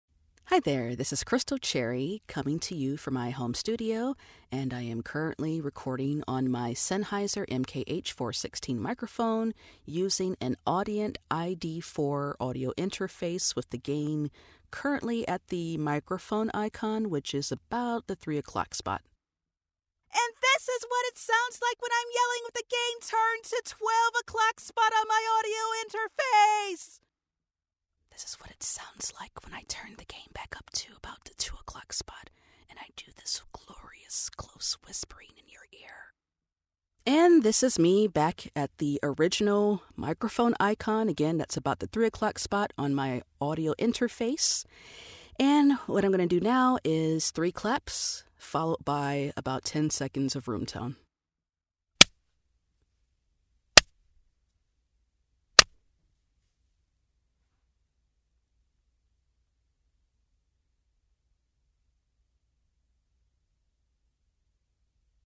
Female
Character, Confident, Corporate, Engaging, Friendly, Natural, Smooth, Warm, Versatile
Coaches and clients have described my voice as warm, full of smile, and versatile.
COMMERCIAL_Demo.mp3